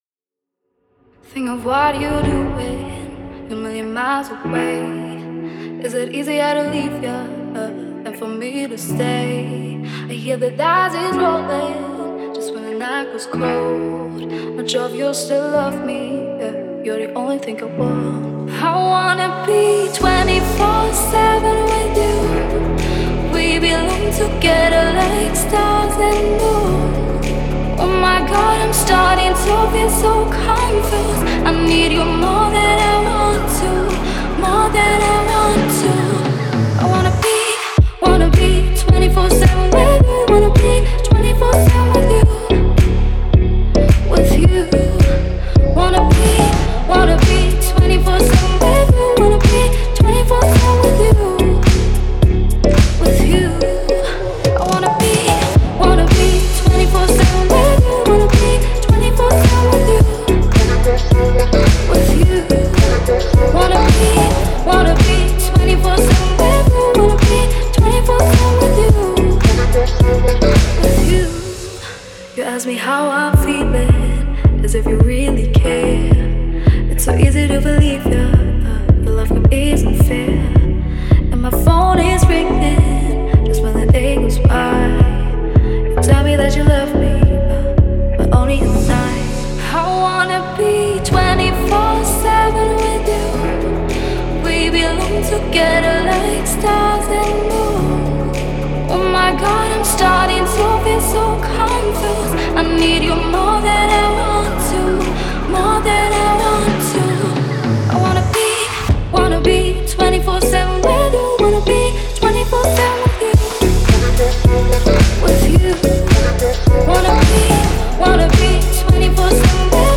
это зажигательная трек в жанре EDM
вокалисткой